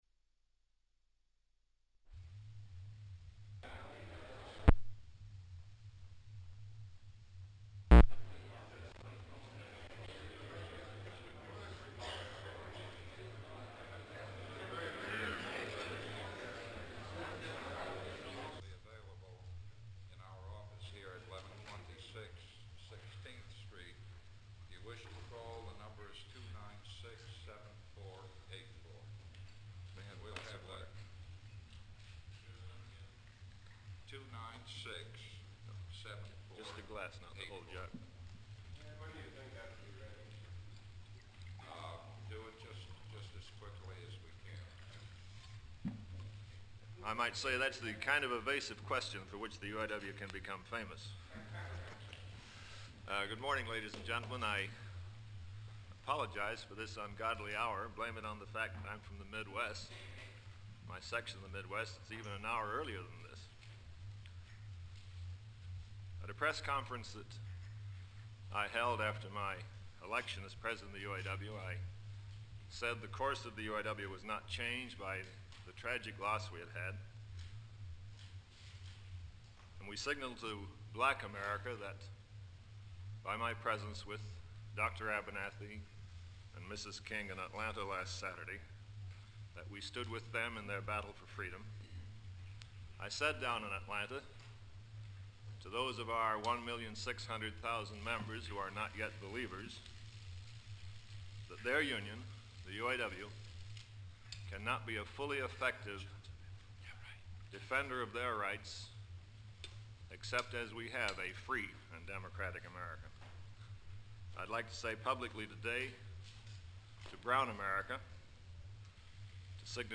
Walter P. Reuther Digital Archive · Leonard Woodcock - Press Conference: UAW East Room - Mayflower Hotel, Washington, DC · Omeka S Multi-Repository